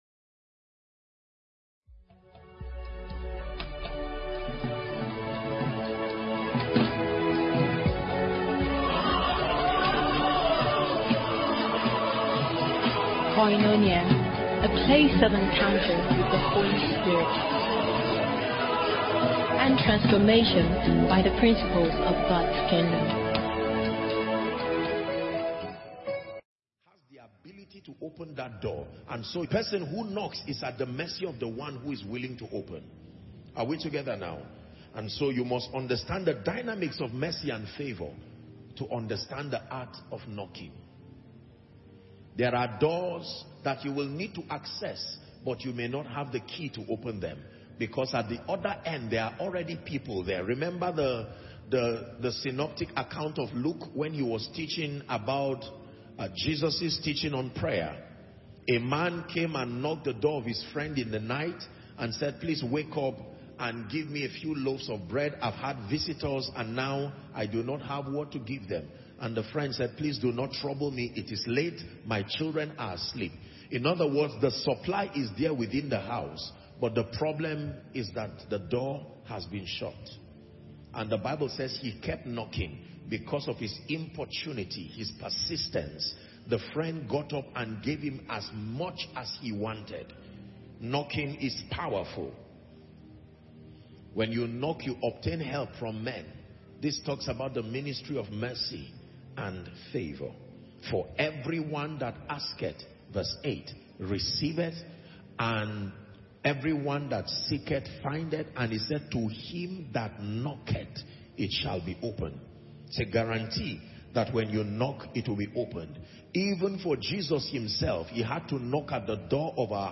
Koinonia 2023